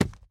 Minecraft Version Minecraft Version snapshot Latest Release | Latest Snapshot snapshot / assets / minecraft / sounds / block / chiseled_bookshelf / insert3.ogg Compare With Compare With Latest Release | Latest Snapshot